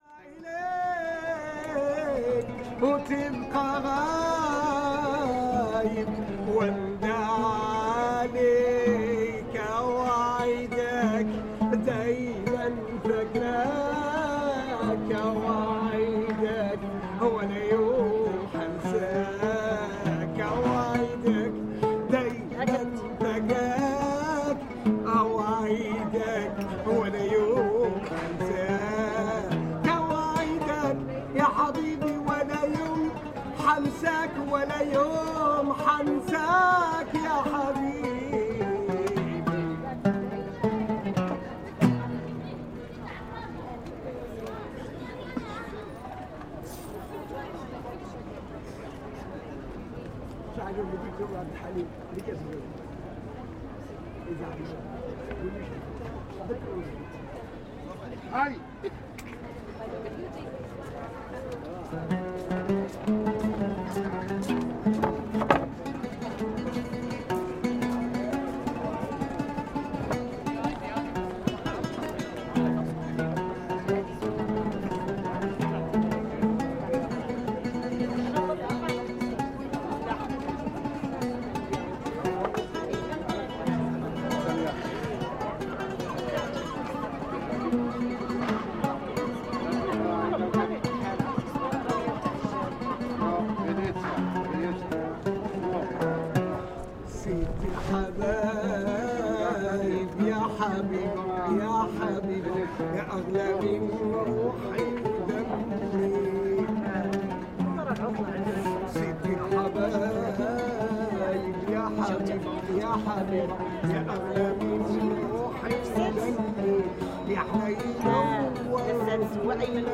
Moroccan busker, Essaouira
An old man busks, singing traditional songs outside the Dolcefreddo cafe in Essaouira, Morocco.